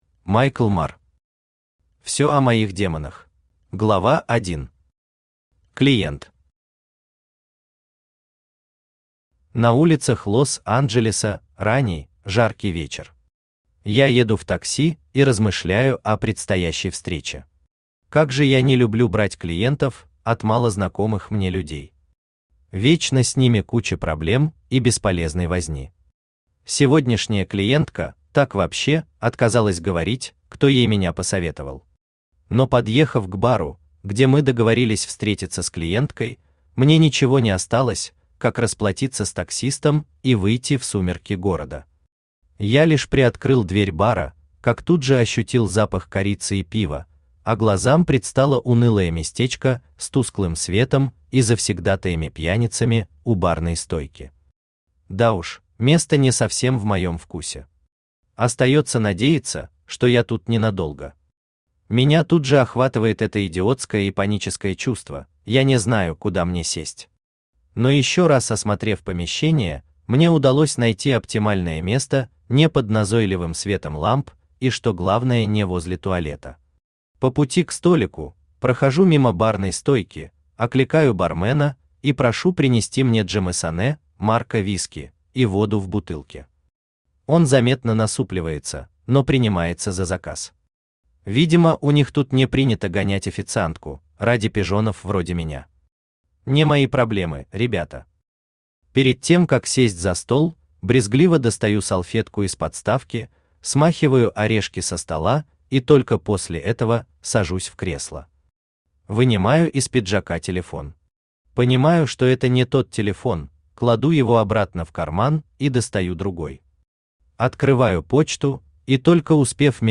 Аудиокнига Всё о моих демонах | Библиотека аудиокниг
Aудиокнига Всё о моих демонах Автор Майкл Мар Читает аудиокнигу Авточтец ЛитРес.